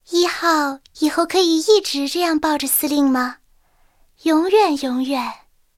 I号誓约语音.OGG